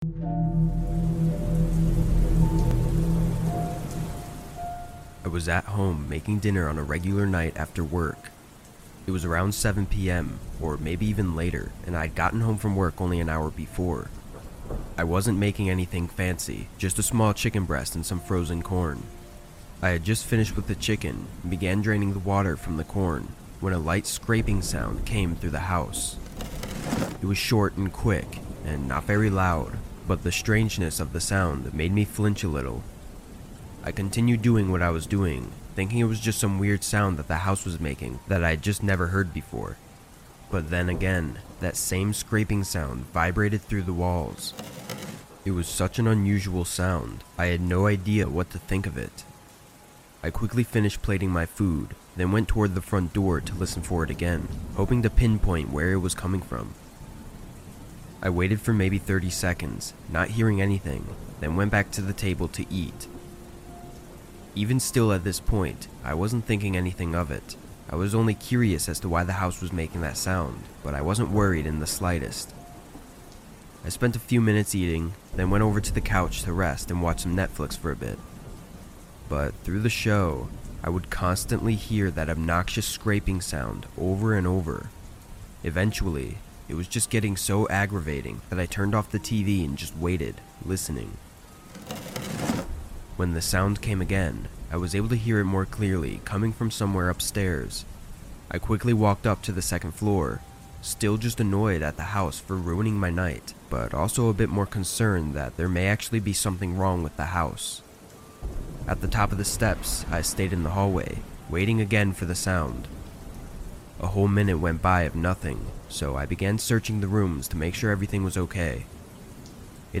Home Alone Horror Stories That Will Leave You Shaken | With Rain Sounds